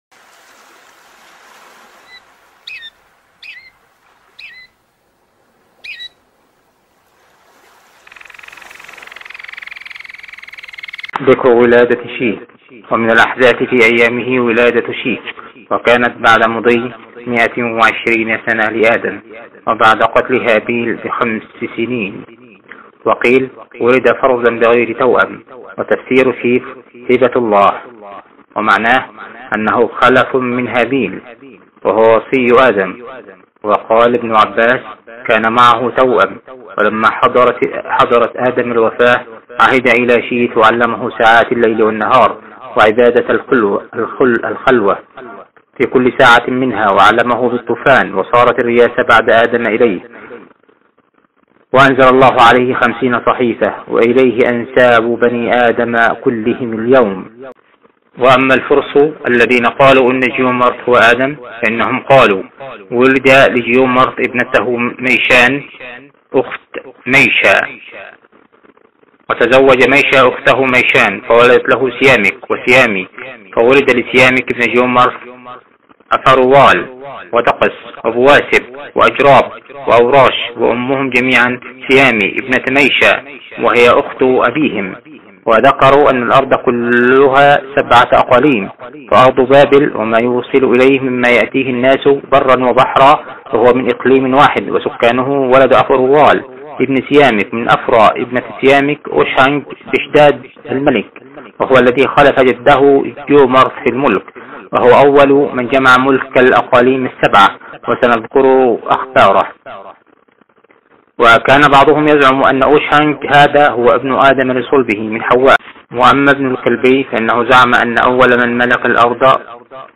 أرشيف الإسلام - الكتب مسموعة - كتب التاريخ - الكامل في التاريخ